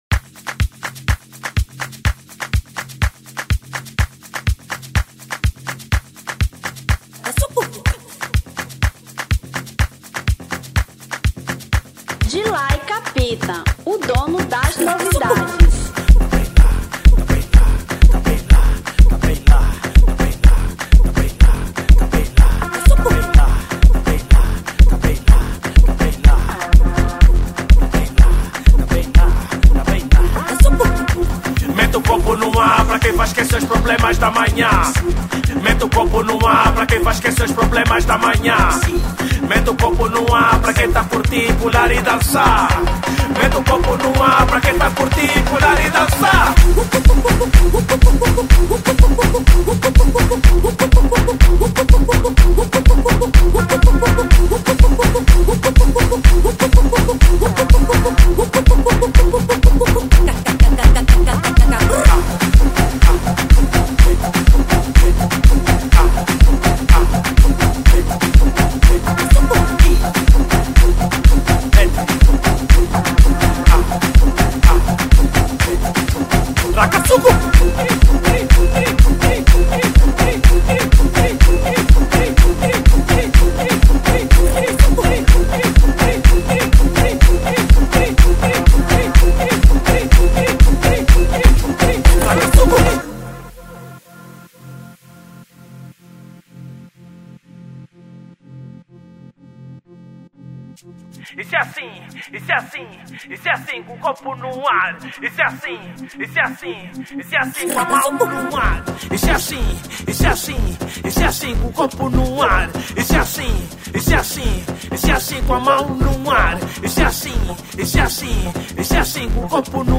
Afro House 2025